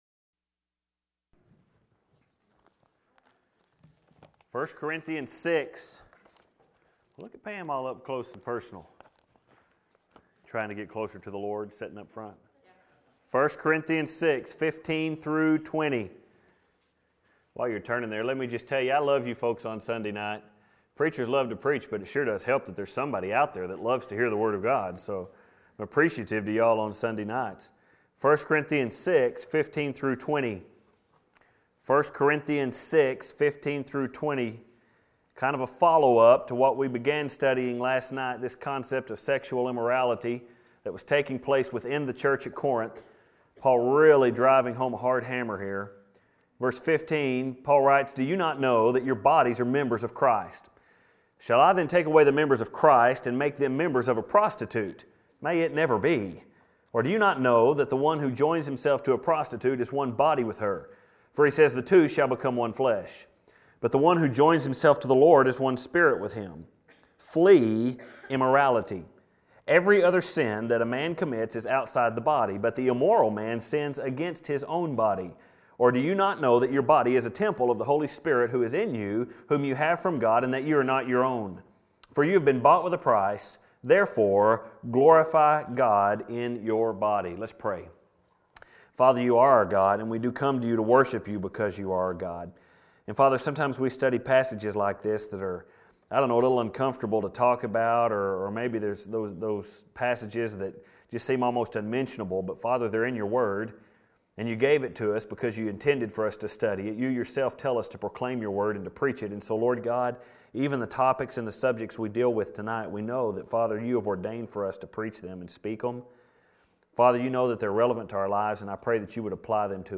1st Corinthians God’s Plan for Your Body – Part 2 - First Baptist Church Spur, Texas
Filed Under: Sermons Tagged With: Corinthians